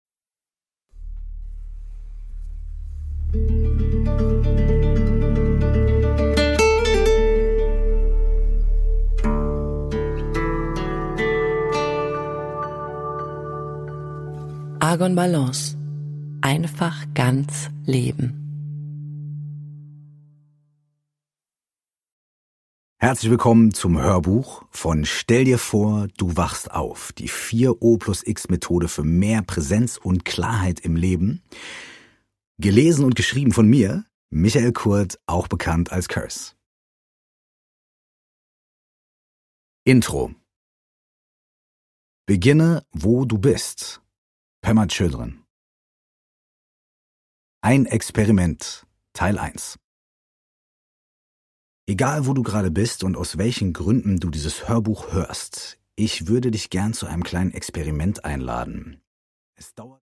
Produkttyp: Hörbuch-Download
Gelesen von: Michael Curse Kurth